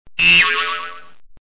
Рингтоны » 3d звуки » Забавная Пружина